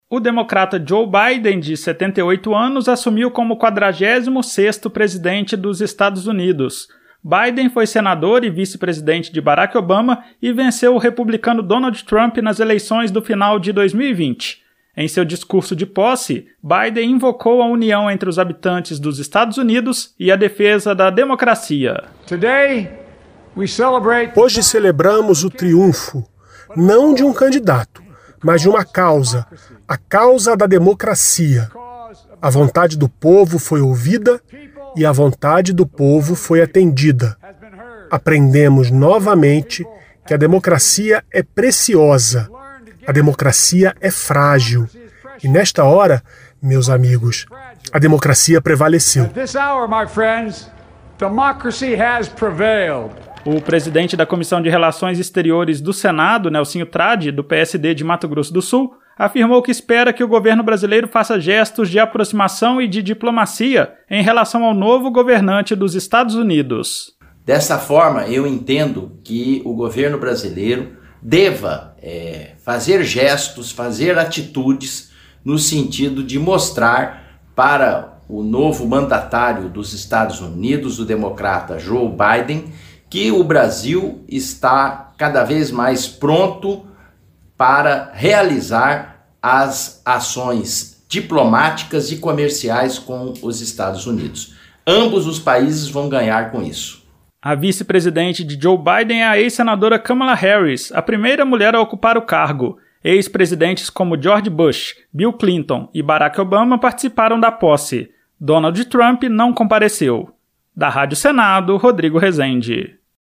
O presidente da Comissão de Relações Exteriores do Senado (CRE), Nelsinho Trad (PSD-MS), afirmou que espera gestos do governo brasileiro em favor do entendimento e da diplomacia com o novo presidente. A reportagem